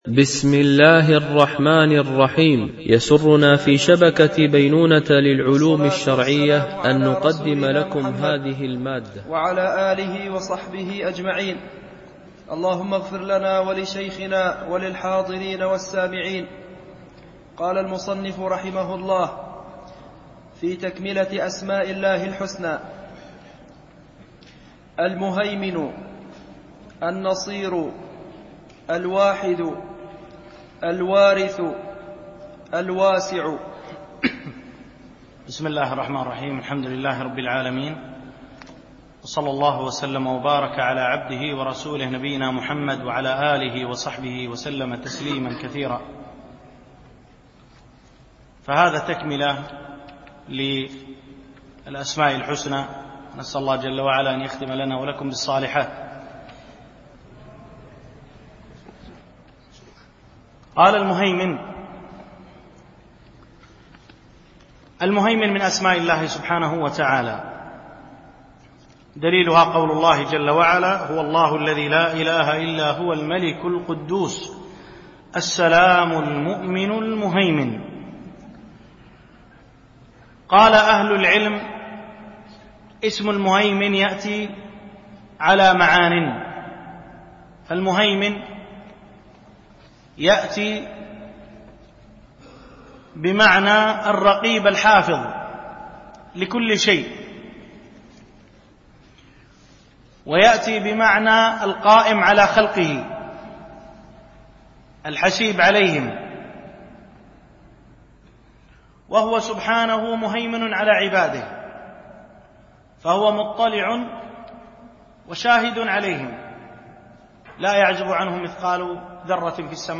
دورة الإمام مالك العلمية الرابعة، بدبي